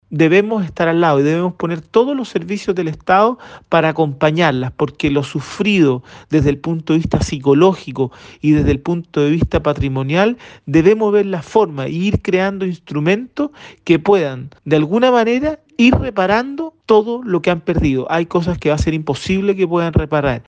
Por su parte y como una forma de apoyar a las víctimas de violencia rural, el delegado presidencial, José Montalva, anunció la rebaja de contribuciones para los dueños de predios que han sido usurpados.